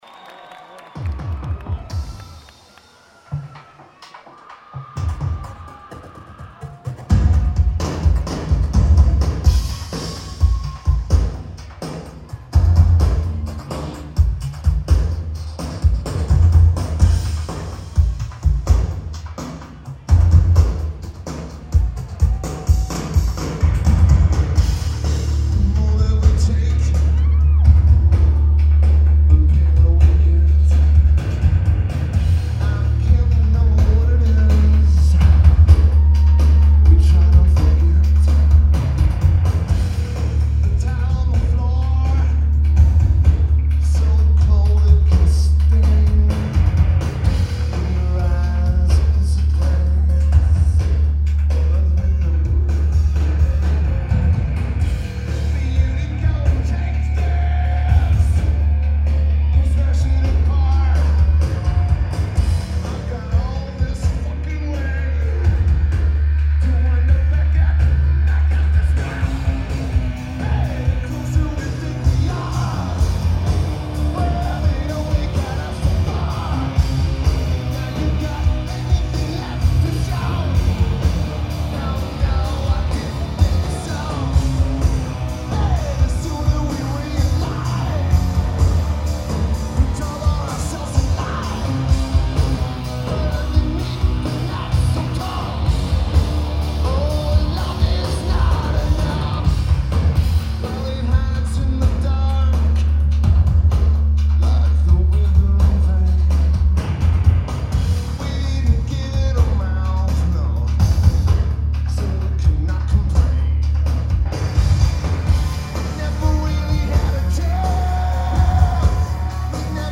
Electric Factory
Notes: Amazing tape.